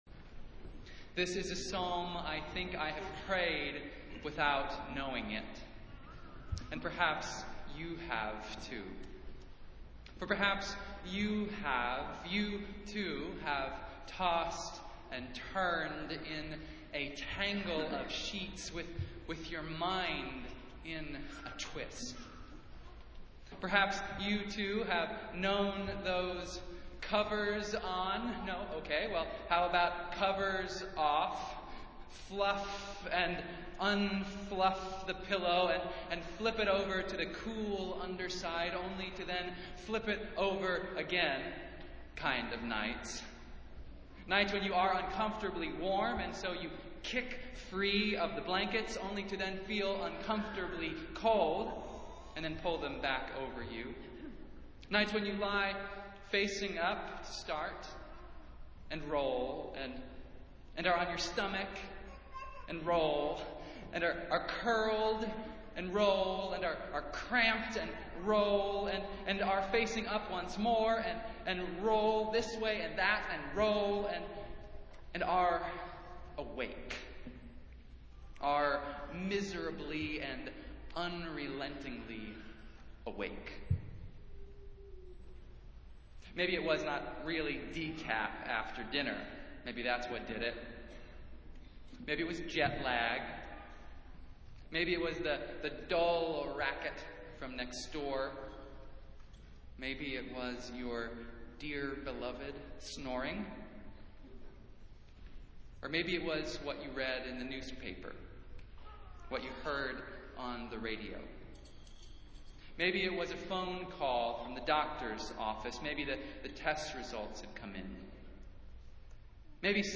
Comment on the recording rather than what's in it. Festival Worship - Twenty-third Sunday after Pentecost | Old South Church in Boston, MA Festival Worship - Twenty-third Sunday after Pentecost